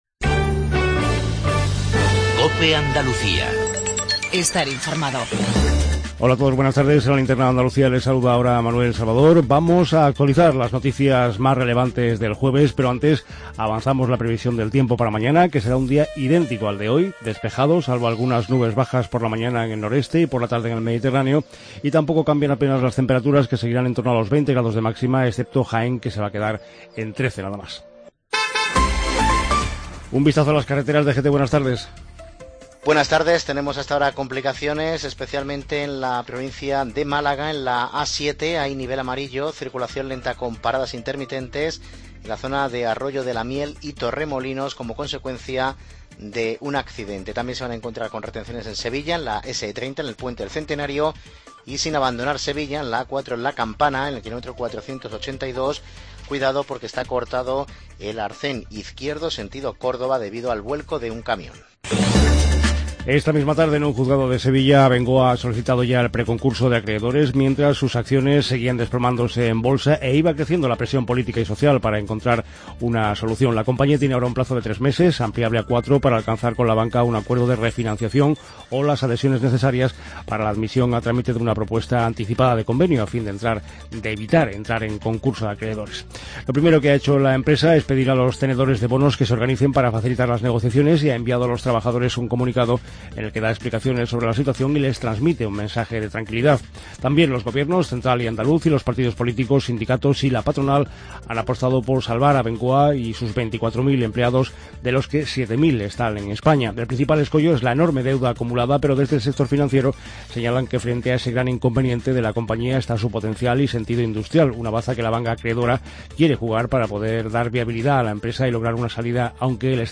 INFORMATIVO REGIONAL TARDE COPE ANDALUCIA